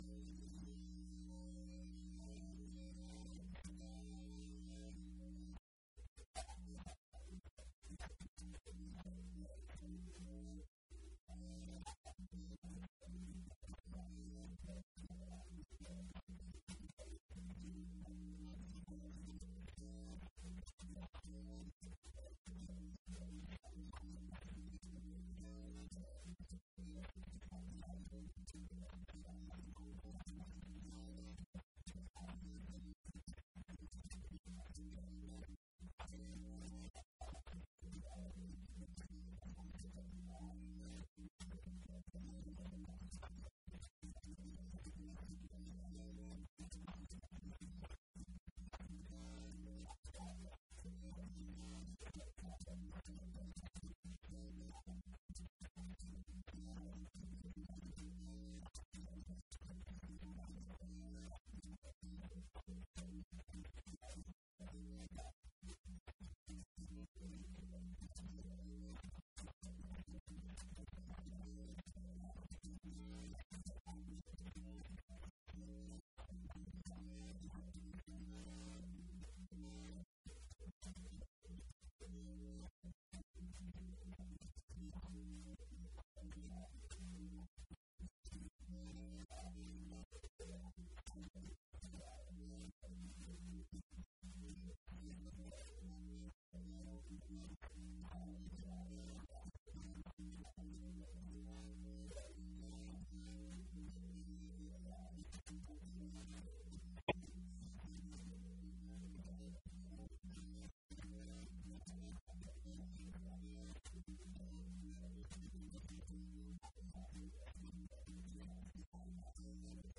This talk presents recent research on addressing the two facets of this problem: How can we make Web applications more secure? How can we detect and block attacks against the components of the World-Wide Web?